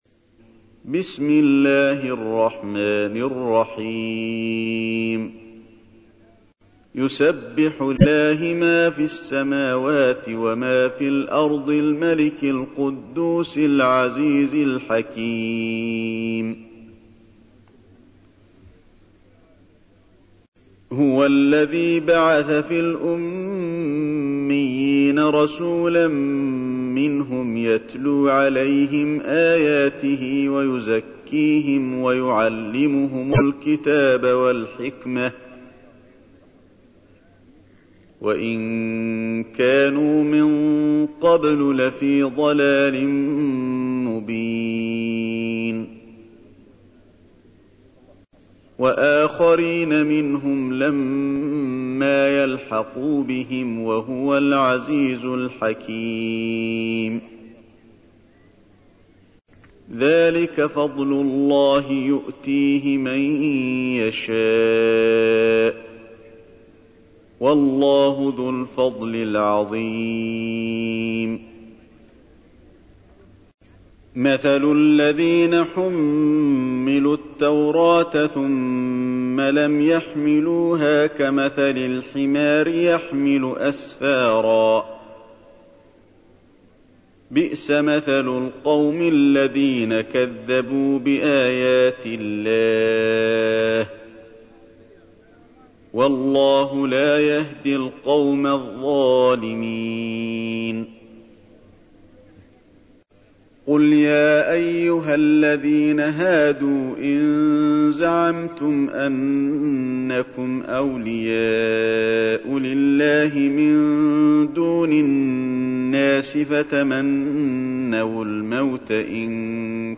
تحميل : 62. سورة الجمعة / القارئ علي الحذيفي / القرآن الكريم / موقع يا حسين